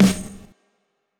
XCF_SNR.wav